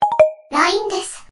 マリンバの通知音と女性ロボット声で「LINEです」を組み合わせたLINEアプリの通知音です。